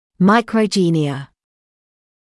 [ˌmaɪkrə’ʤiːnɪə][ˌмайкрэ’джиːниэ]микрогения